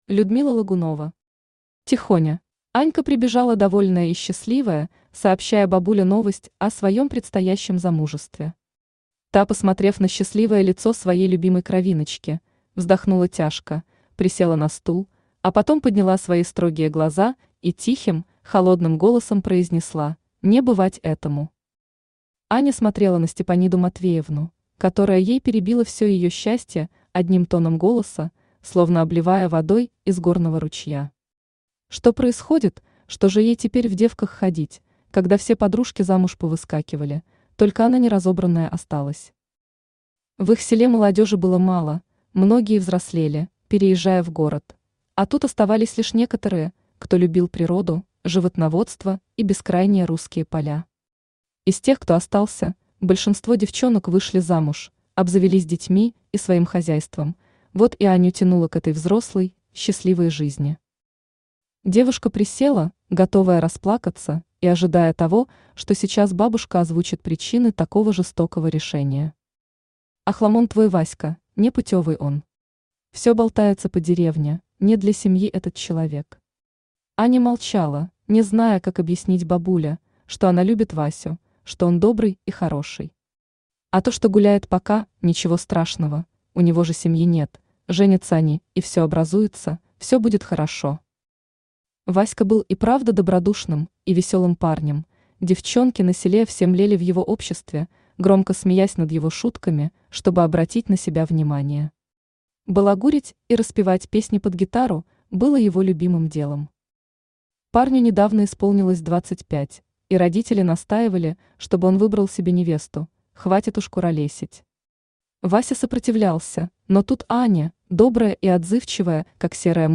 Аудиокнига Тихоня | Библиотека аудиокниг
Aудиокнига Тихоня Автор Людмила Логунова Читает аудиокнигу Авточтец ЛитРес.